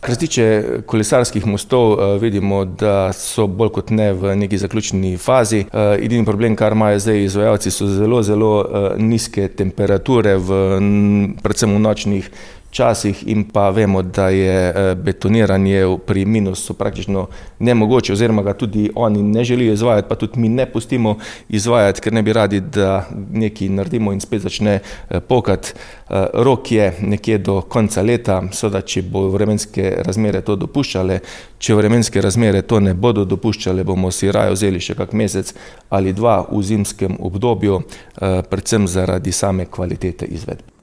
V Slovenj Gradcu so avgusta začeli z rekonstrukcijo nesrečnih kolesarskih mostov s ciljem obnoviti jih še do konca leta. Kako napredujejo, je pojasnil župan Mestne občine Slovenj Gradec Tilen Klugler: